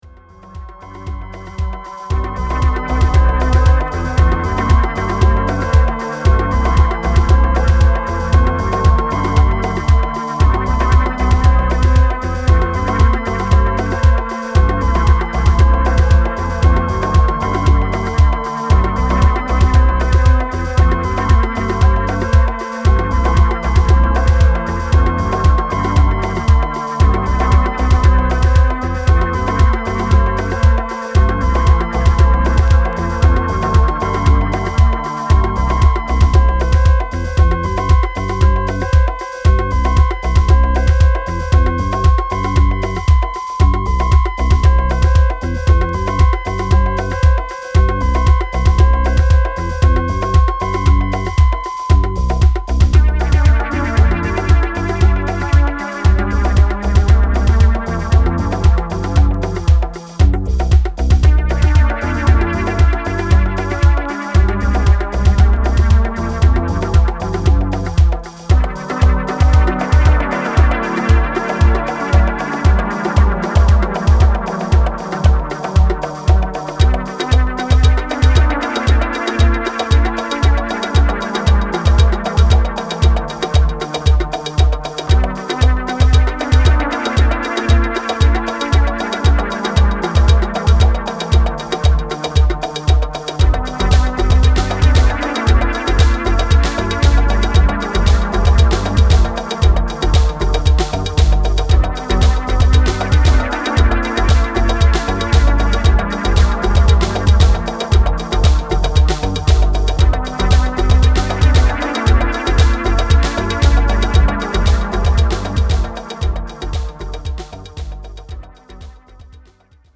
Italian techno tracks from early 90s.